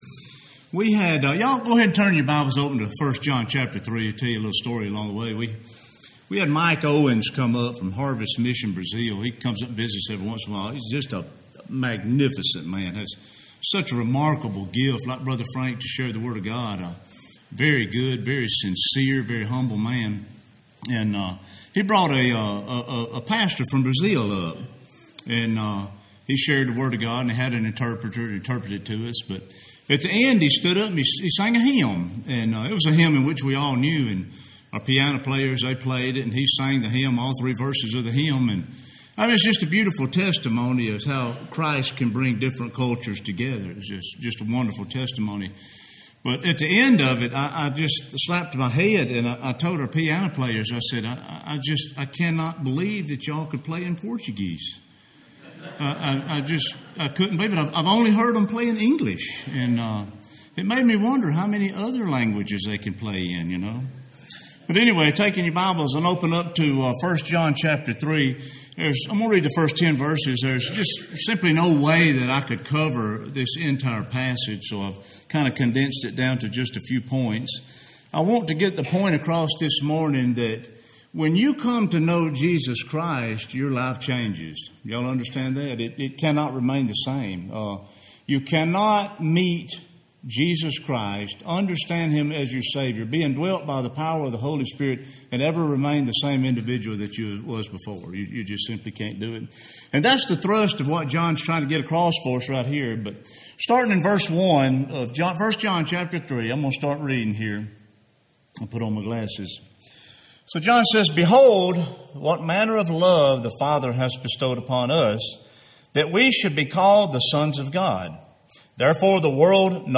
Home › Sermons › Incompatibility Of Sin And Christians